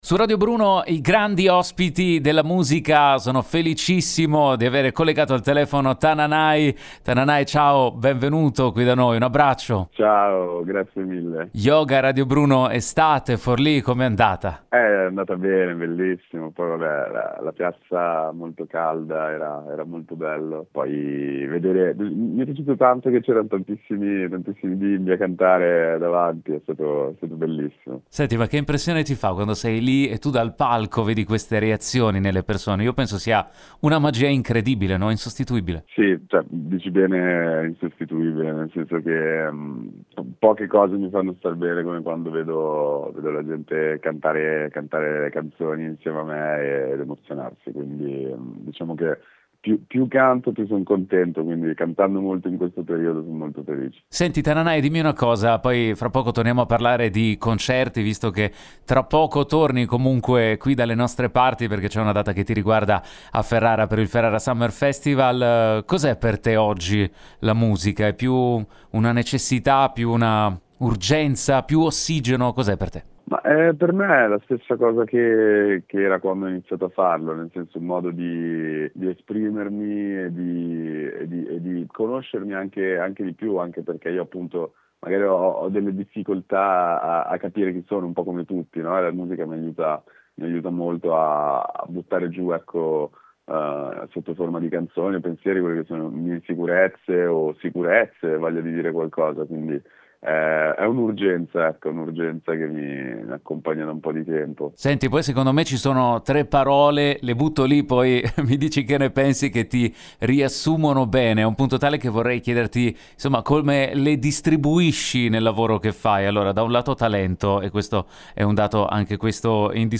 Abbiamo raggiunto Tananai al telefono dopo la data di Forlì, del 2 luglio, di Yoga Radio Bruno Estate.